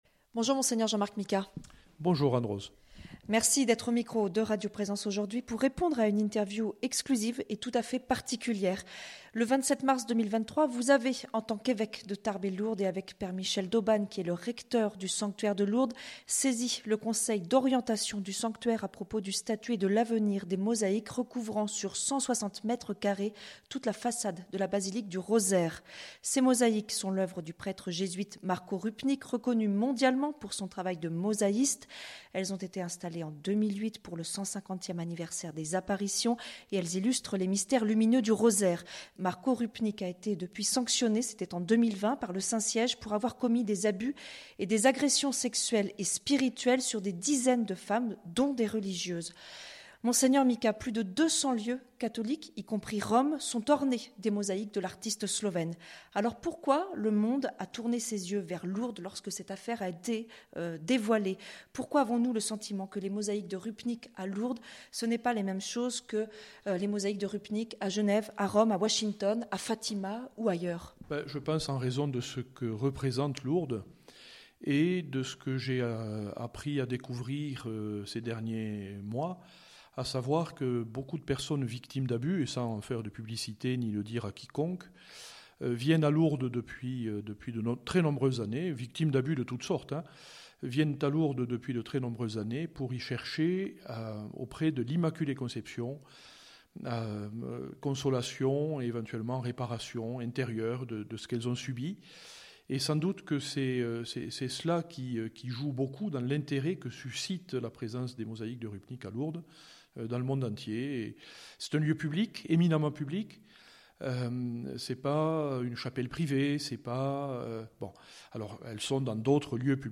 Communiqué de Mgr Jean-Marc Micas, évêque de Tarbes et Lourdes.